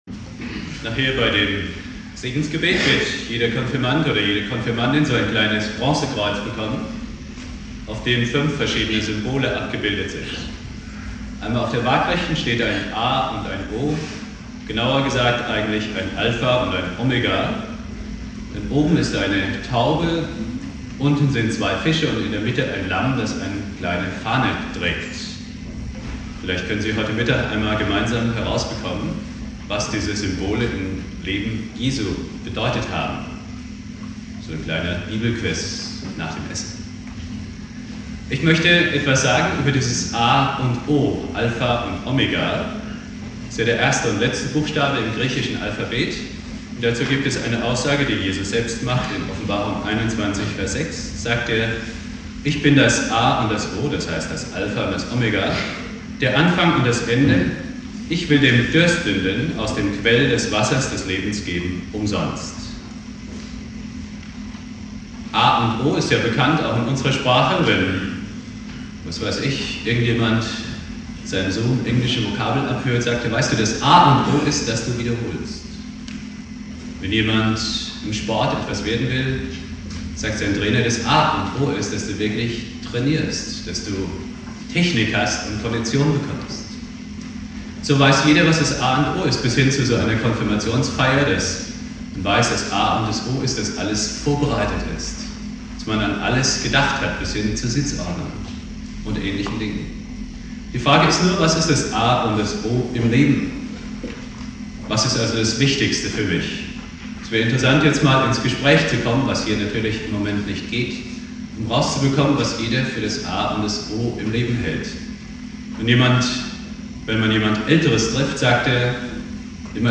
Predigt
Thema: "Das A und O im Leben" (Konfirmation Obertshausen) Bibeltext